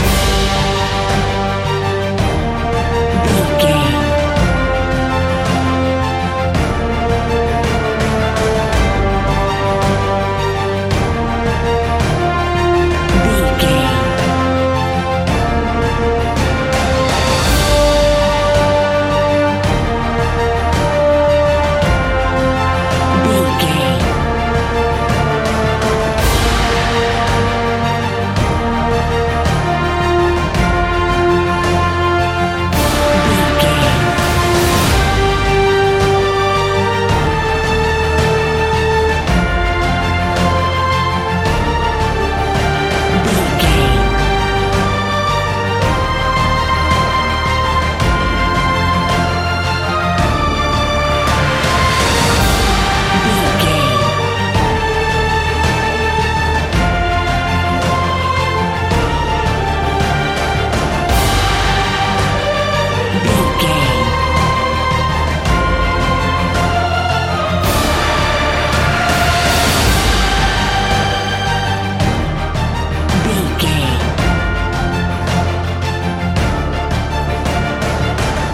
Epic / Action
Uplifting
Aeolian/Minor
heavy
pompous
powerful
brass
cello
double bass
drums
horns
strings
synthesizers
violin
hybrid